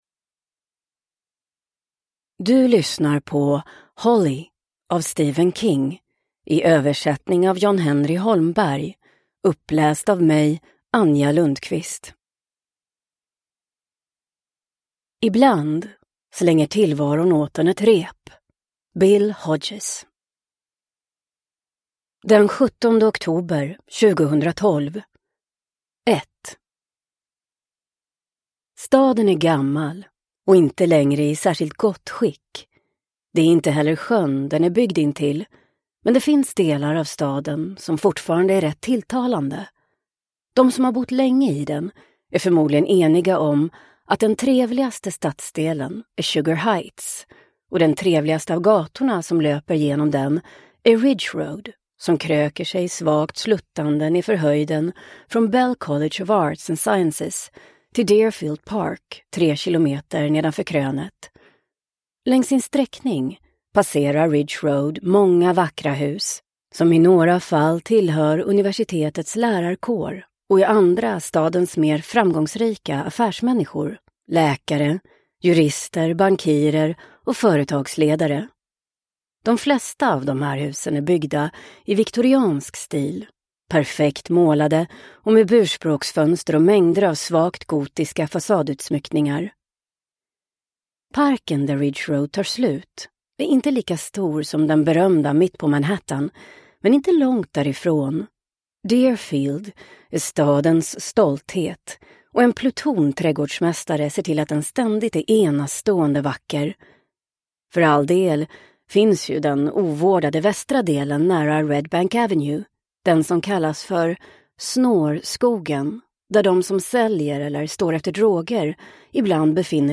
Holly – Ljudbok – Laddas ner